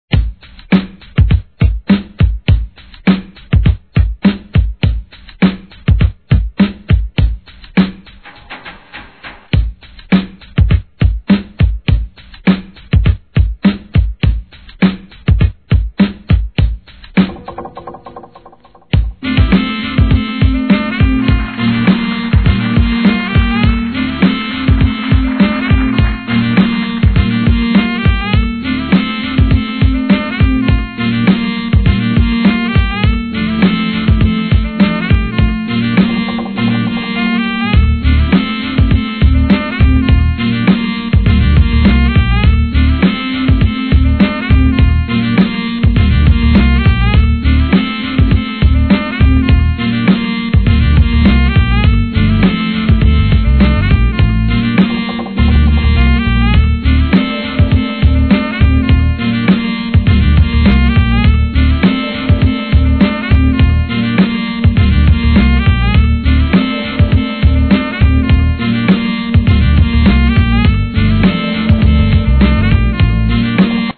HIP HOP/R&B
2003年インスト・ブレイクの大傑作!